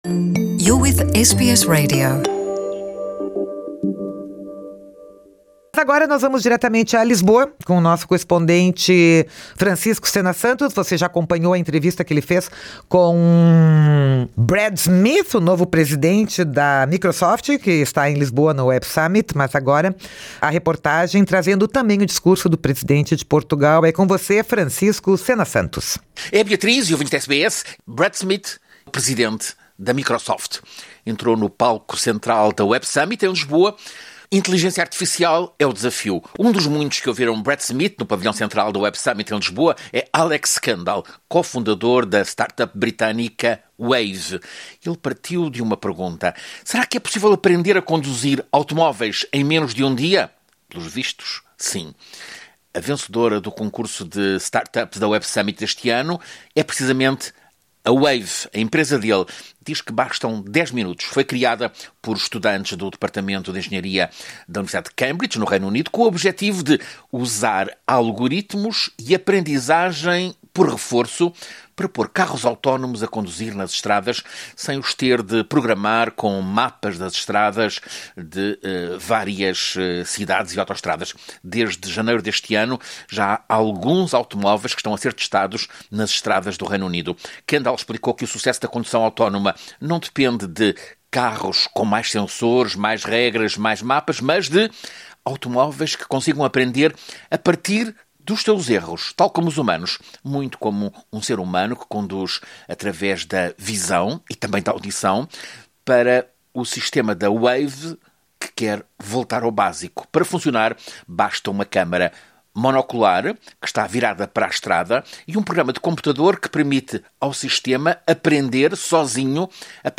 Reportagem da Web Summit em Lisboa, com o discurso de encerramento de Marcelo Rebelo de Sousa, presidente de Portugal, e com o vencedor da startup do ano.